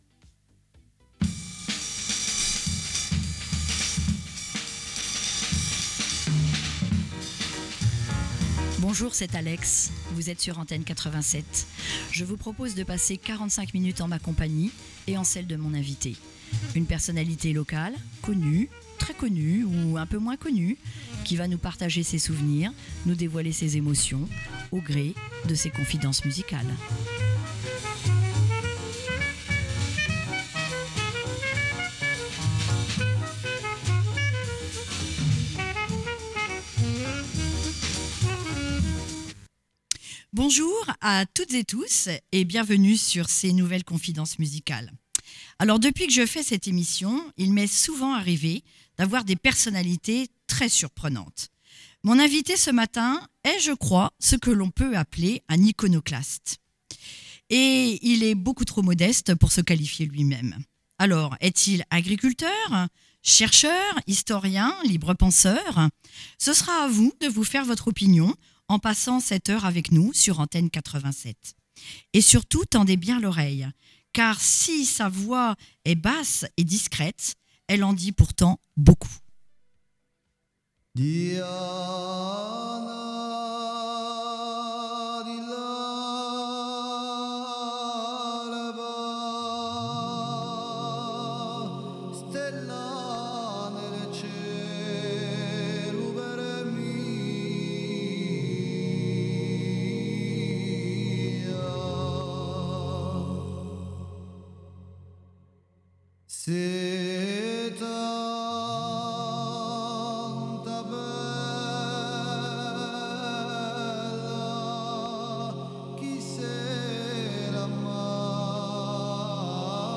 Chaque semaine, partez à la rencontre d'un acteur local , d'un artisan passionné , d'un habitant inspirant … et pourquoi pas vous ! Des histoires authentiques, des parcours étonnants et des confiances partagées au rythme d'une sélection musicale qui vous fera voyager.